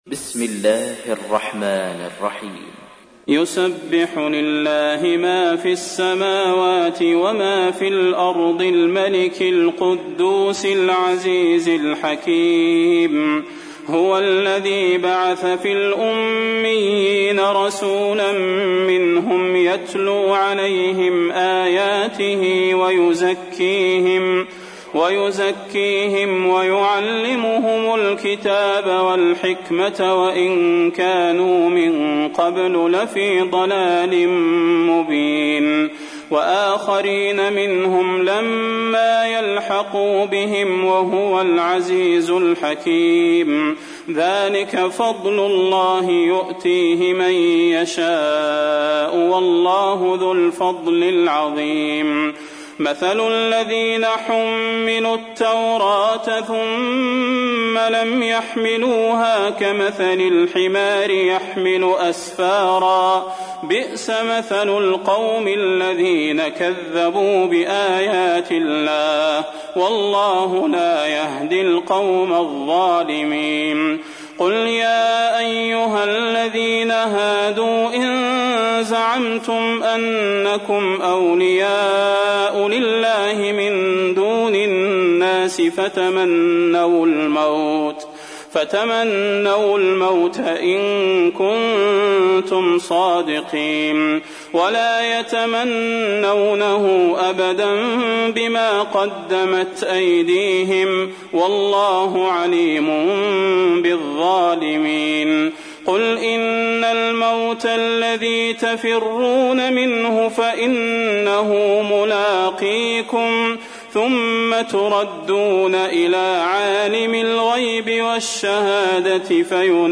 تحميل : 62. سورة الجمعة / القارئ صلاح البدير / القرآن الكريم / موقع يا حسين